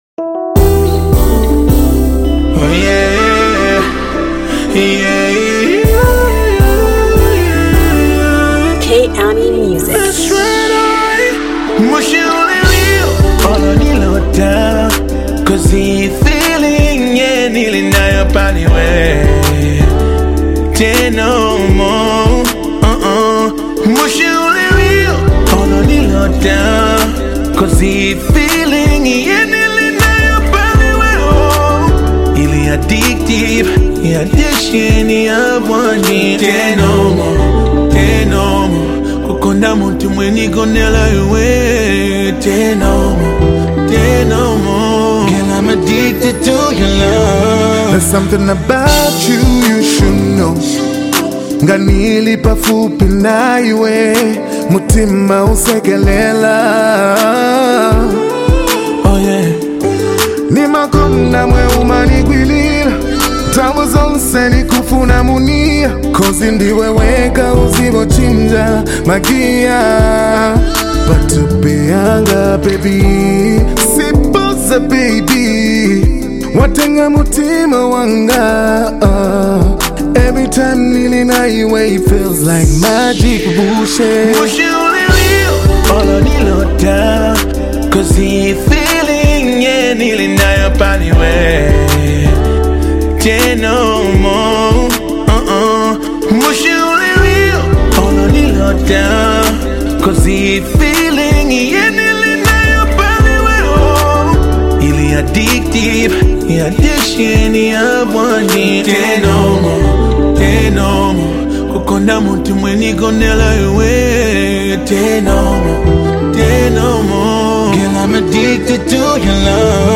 Love Song
smooth and laid back R&B vibe